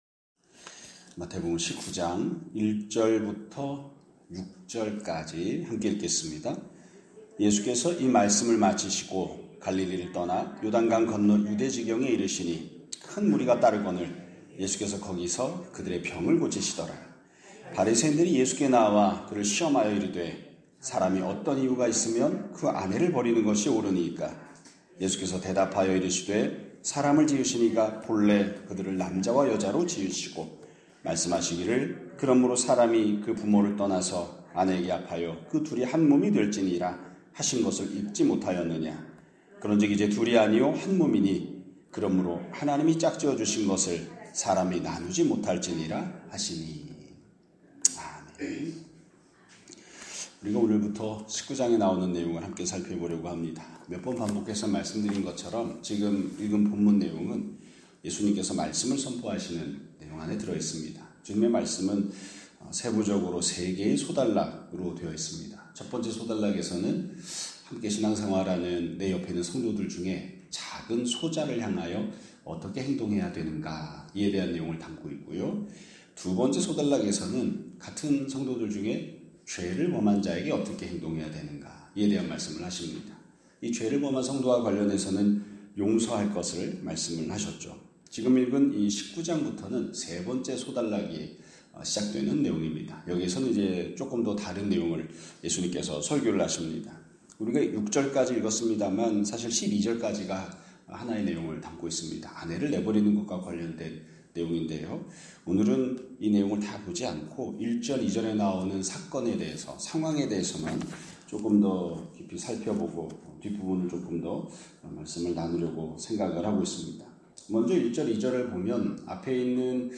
2025년 12월 29일 (월요일) <아침예배> 설교입니다.